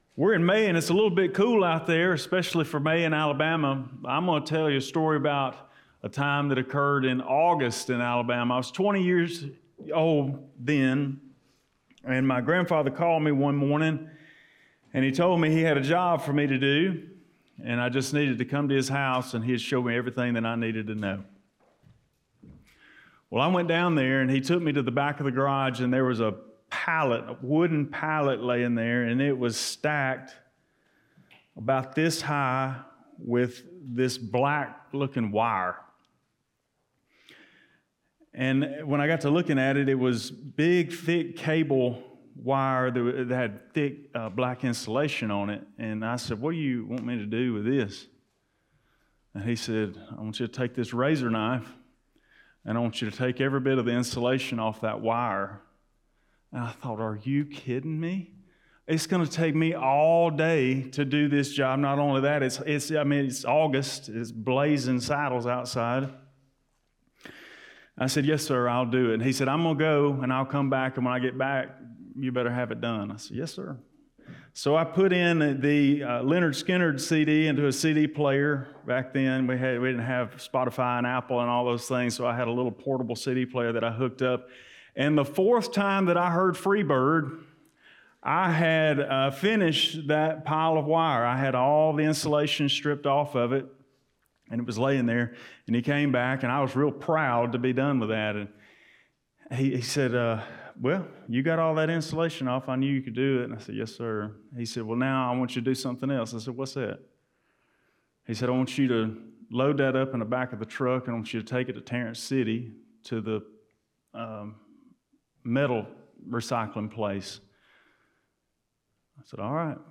05.04.2025 Sunday Sermon from Matthew 5:1-4